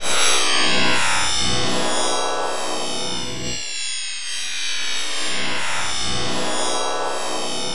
Index of /90_sSampleCDs/Spectrasonic Distorted Reality 2/Partition D/07 SCI-FI 1